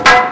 Falling Wood Sound Effect
falling-wood-2.mp3